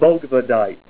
Help on Name Pronunciation: Name Pronunciation: Bogvadite + Pronunciation
Say BOGVADITE